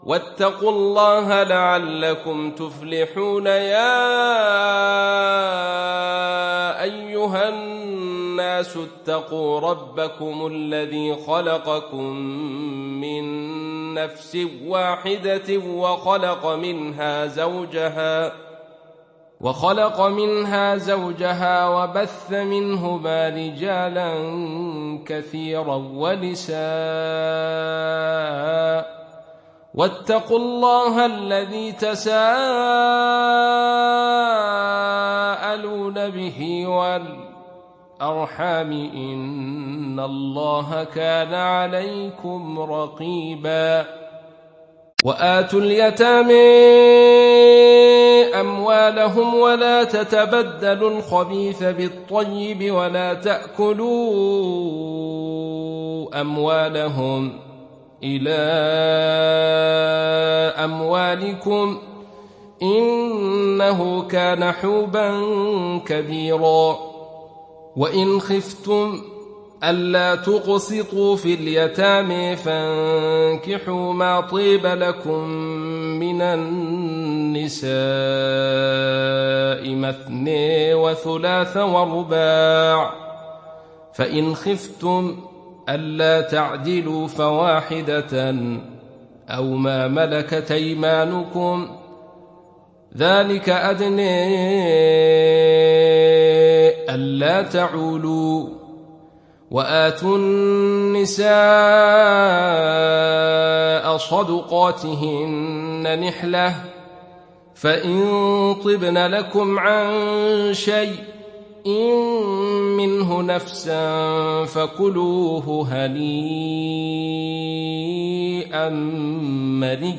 Surah Annisa Download mp3 Abdul Rashid Sufi Riwayat Khalaf from Hamza, Download Quran and listen mp3 full direct links